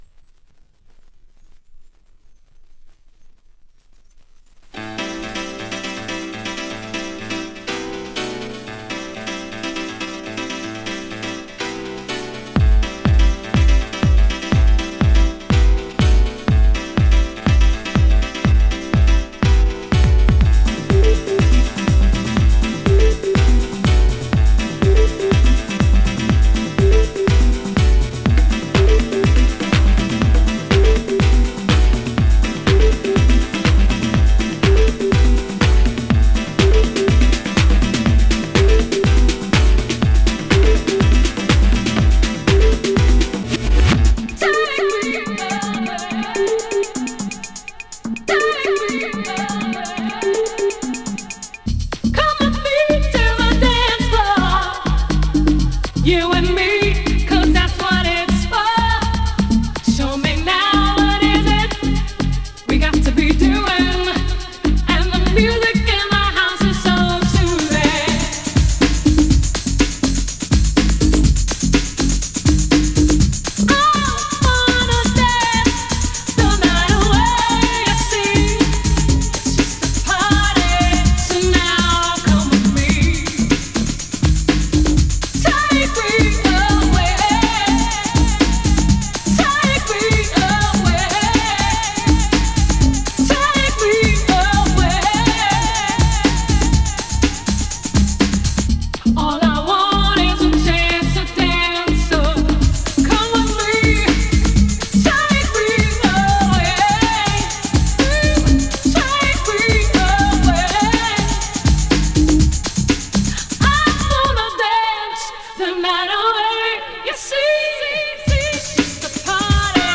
Based on the prominent reliance on a breakbeat
So I’m sharing a vinyl rip here.